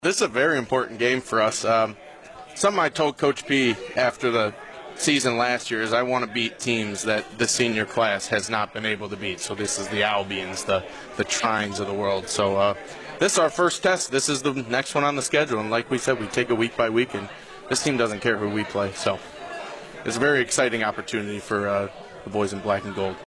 a talk show at Fricker’s in Adrian Monday nights from 7-8pm